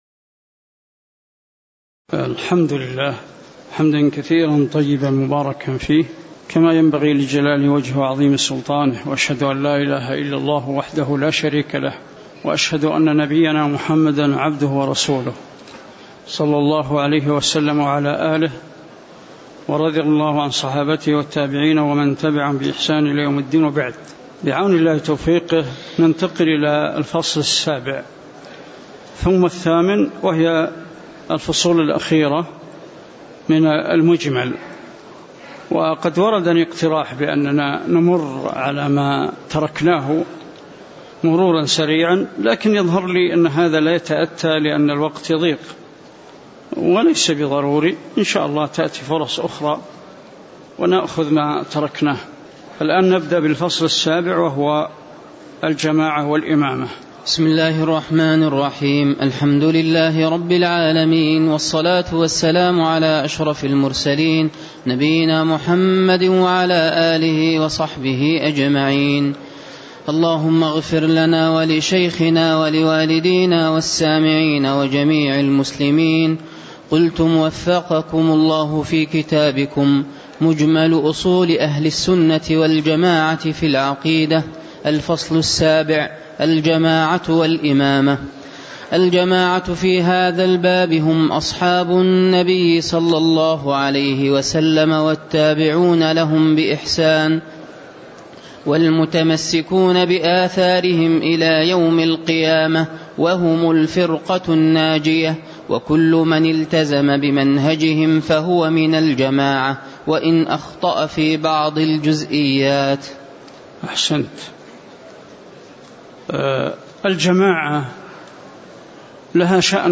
تاريخ النشر ١ جمادى الأولى ١٤٣٩ هـ المكان: المسجد النبوي الشيخ